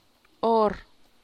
rcyi[rcyi’ih]